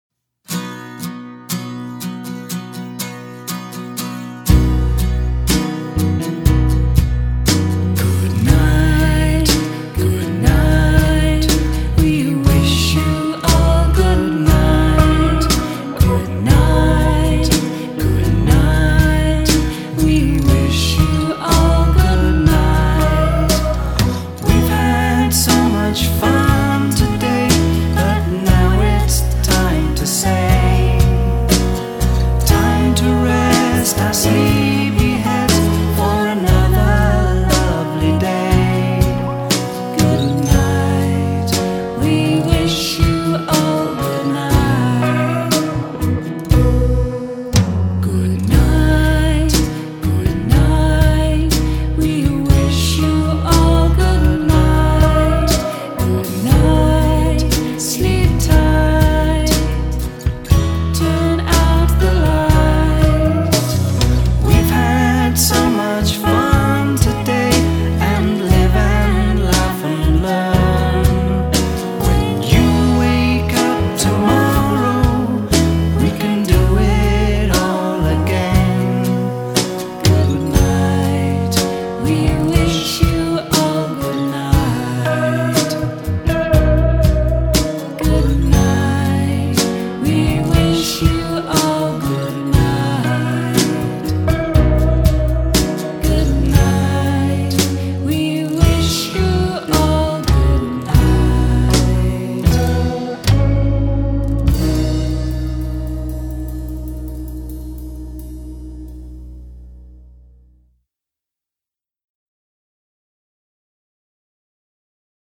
Lullaby